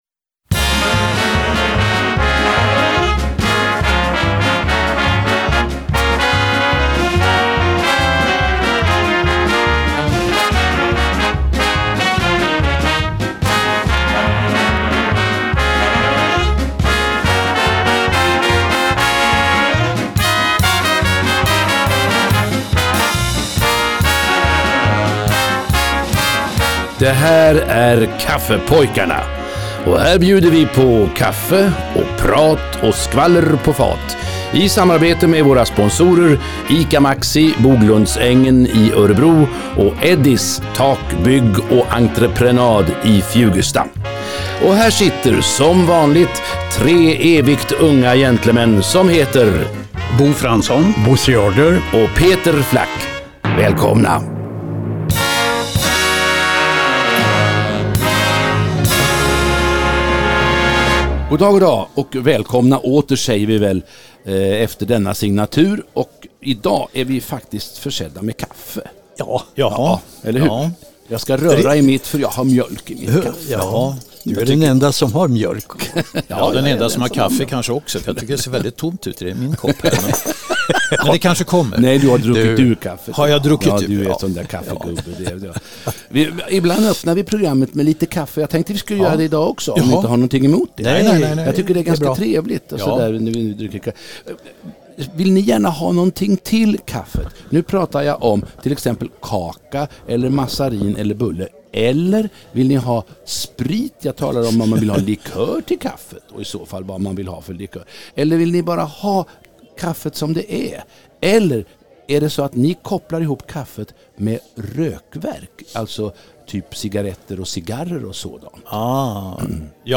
Med sprittande musik, sprudlande prat och sprillans inaktuellt vetande.